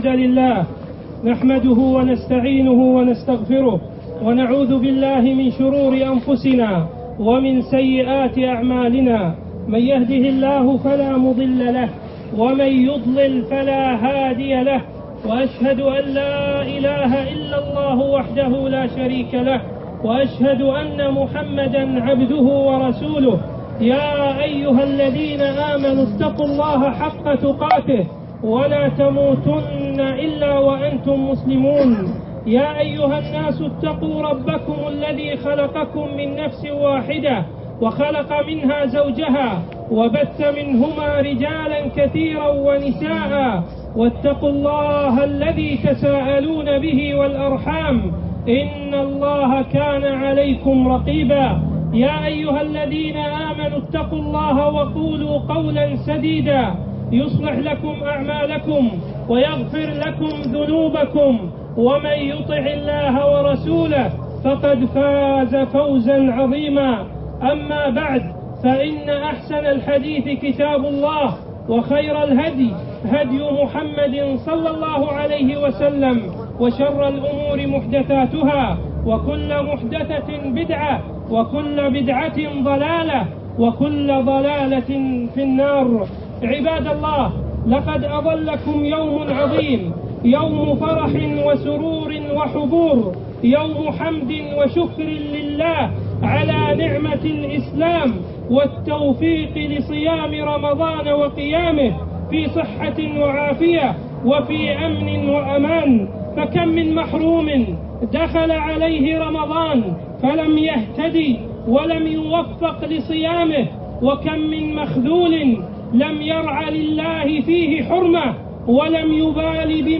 خطبة عيد الفطر 1436هـ